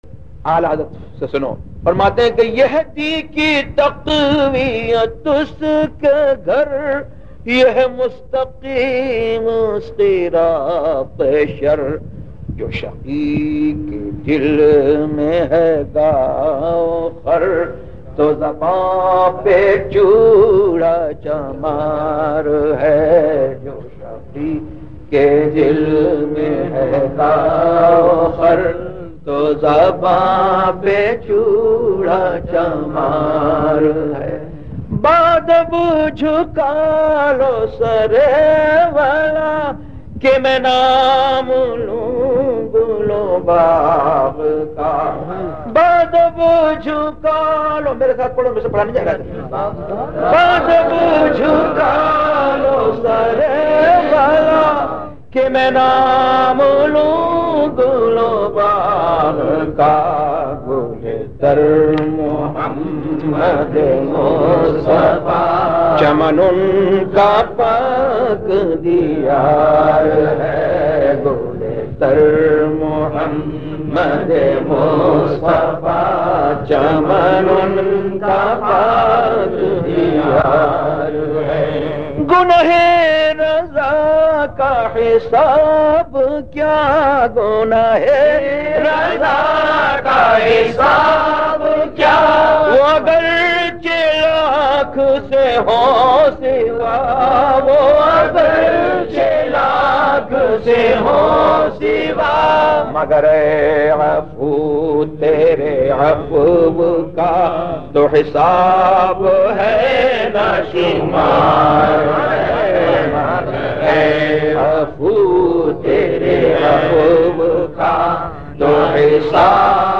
نعت